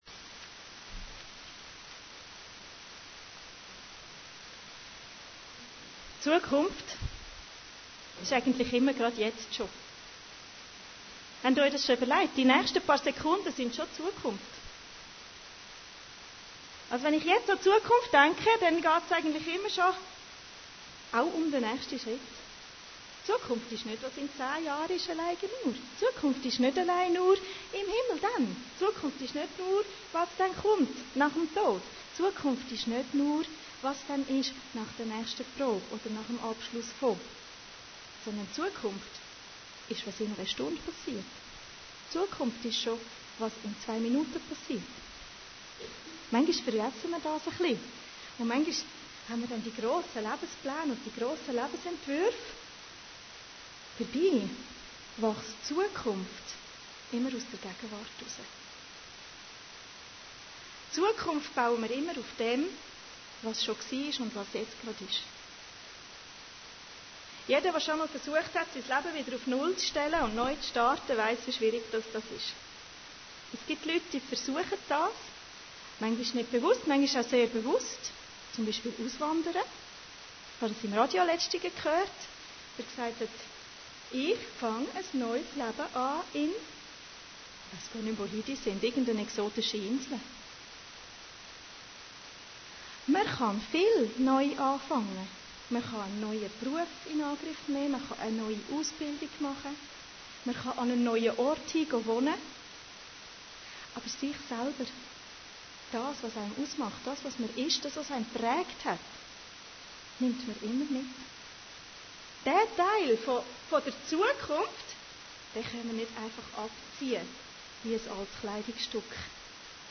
Predigten Heilsarmee Aargau Süd – Zukunft